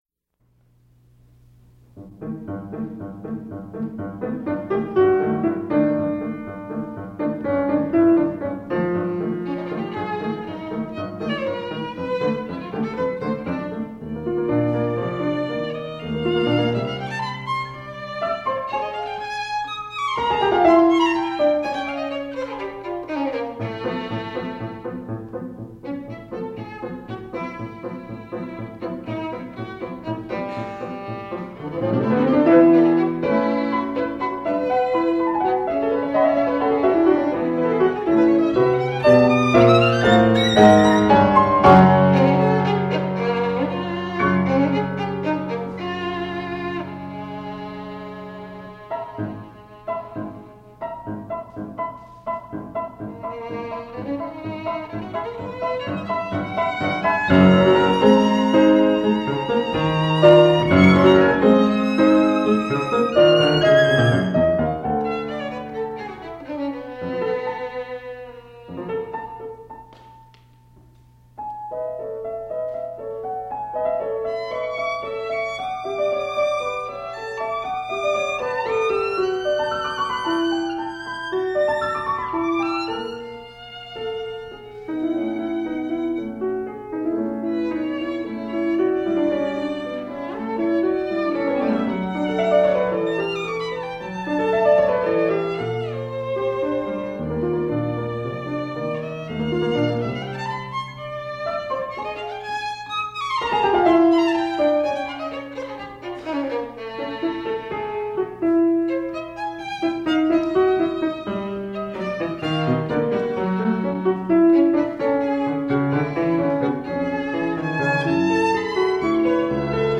This recording is from that premiere.
violin, piano